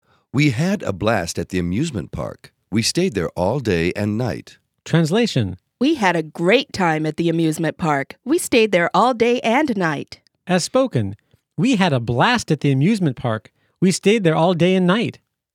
ネイティブのリアル発音:
We had a blast ’it the amusement park! We stayed there
all day ’n night!